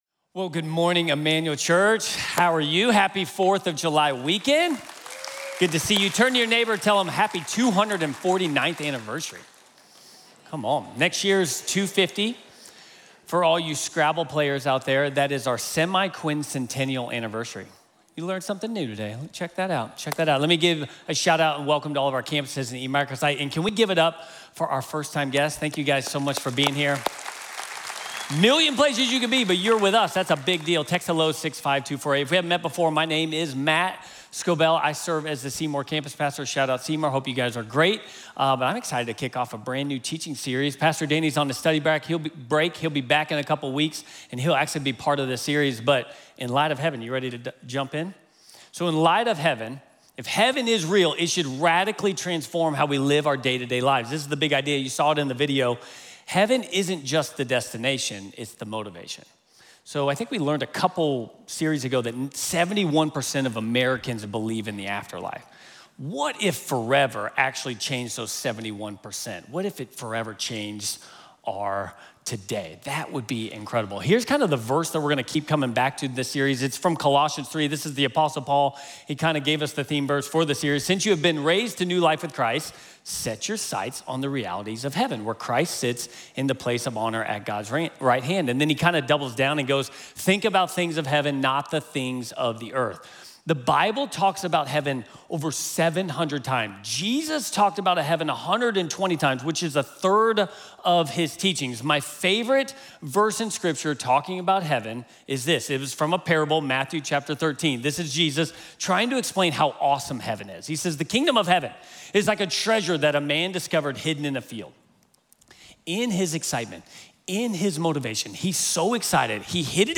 When the weight of the world becomes too much, many of us begin to question where God is in the midst of it. In this message